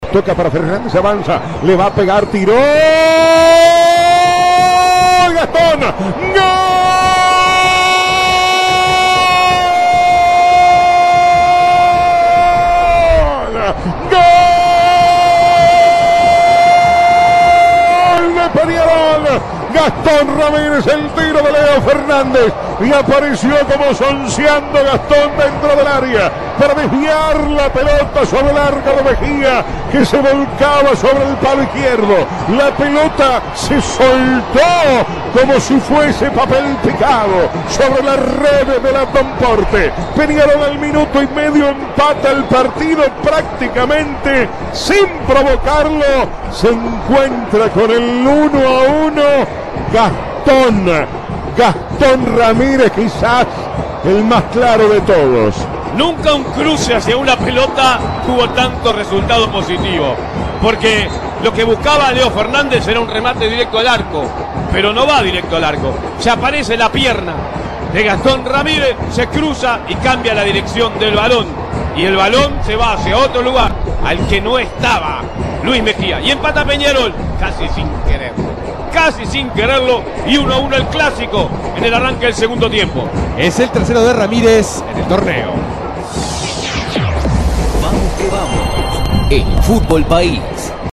El vibrante triunfo tricolor en la voz del equipo de Vamos que Vamos